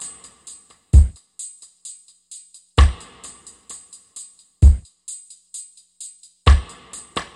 OVERDRIVE MUSIC - Boucle de Batteries - Drum Loops - Le meilleur des métronomes
Dub / Reggae 2
Straight / 65,164 / 2 mes
Dub_2.mp3